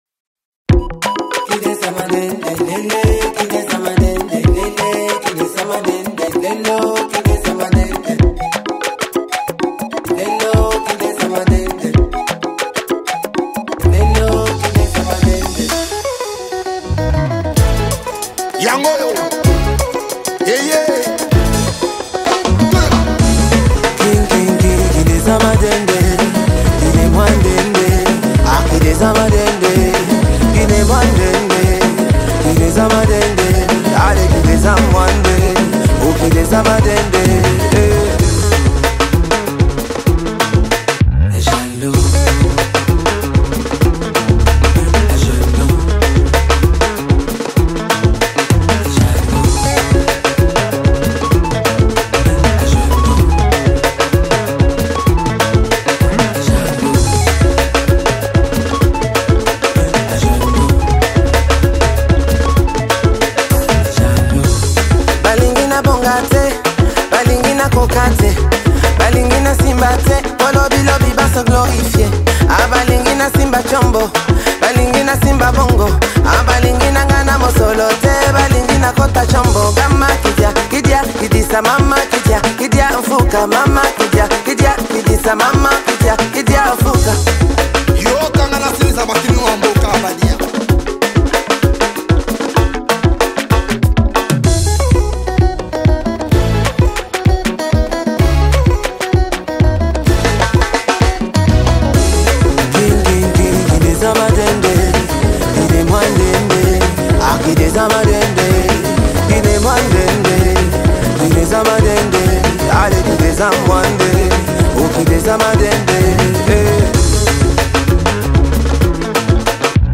| Afro pop